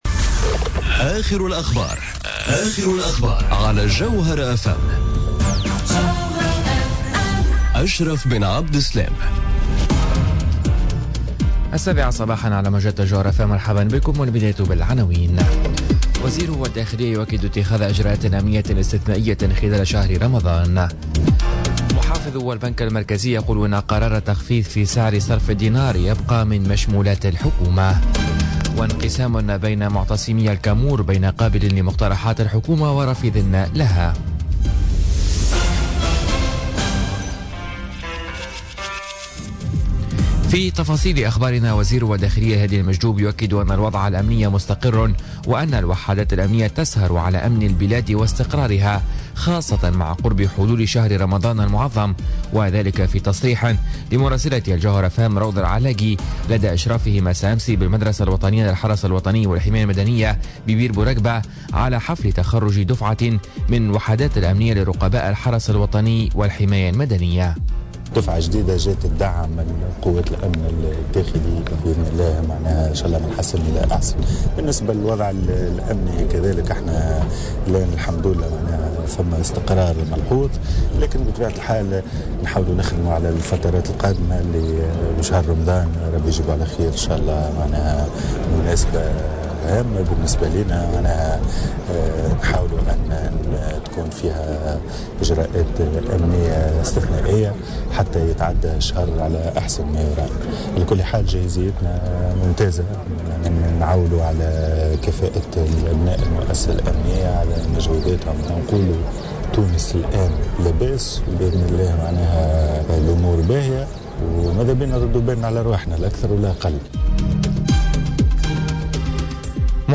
نشرة أخبار السابعة صباحا ليوم الإربعاء 17 ماي 2017